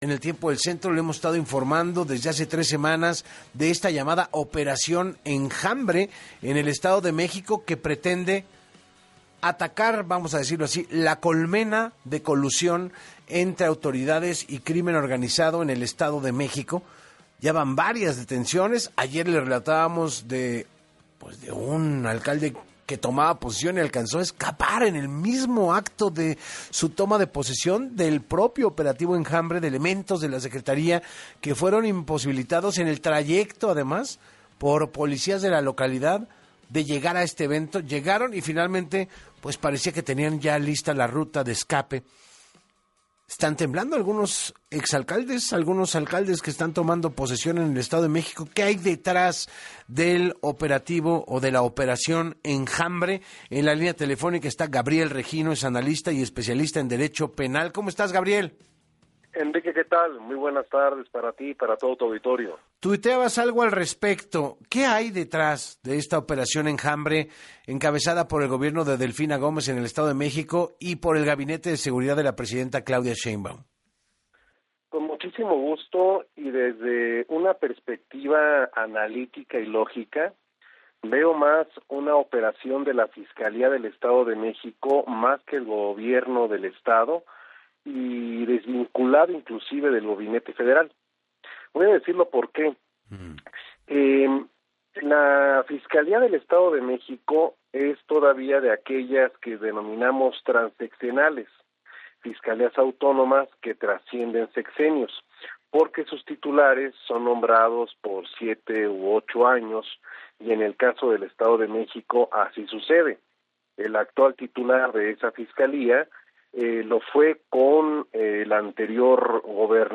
EN VIVO